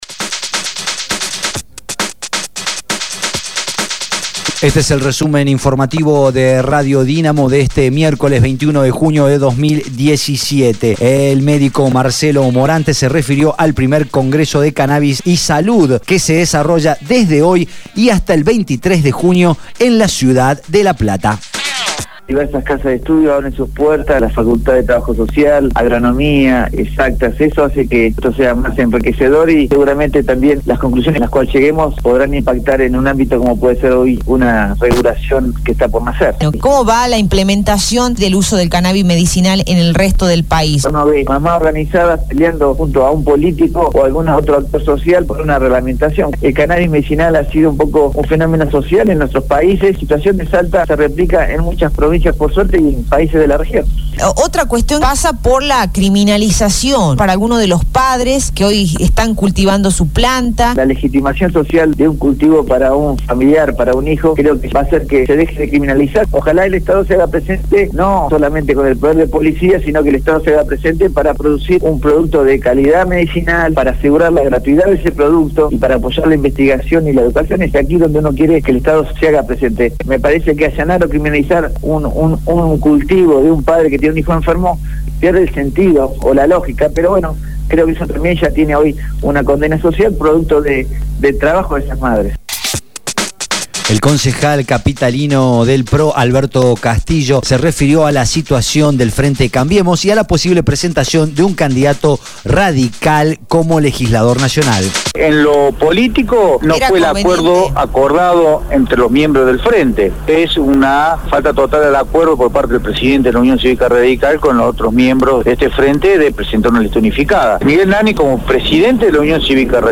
Resumen Informativo de Radio Dinamo del día 21/06/2017 1° Edición